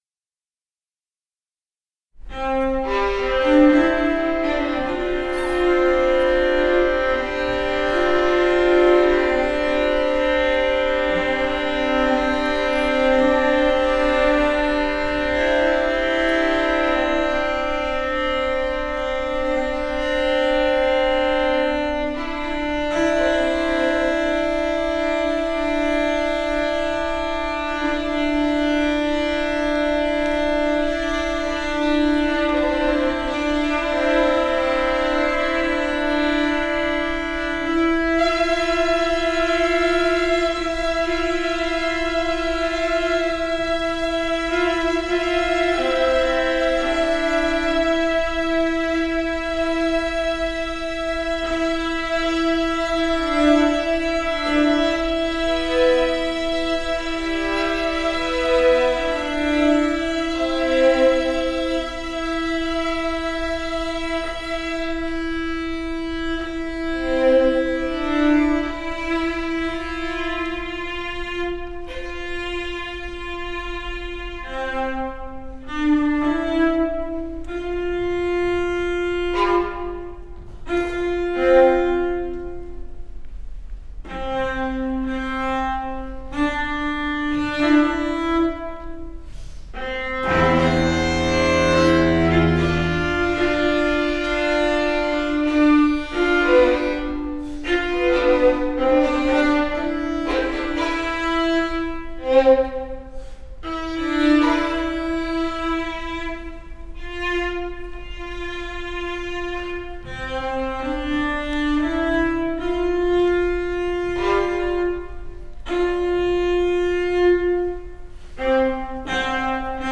(2009) string quartet
Wesleyan University, Nov 2009 listen